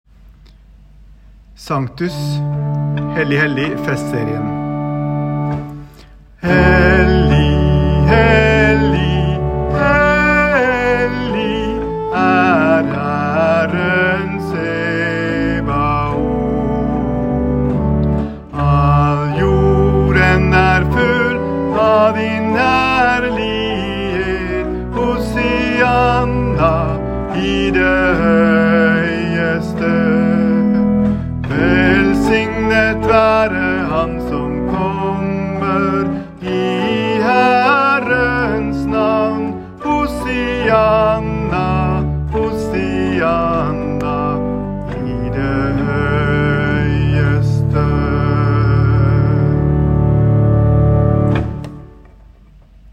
Lydfiler til øving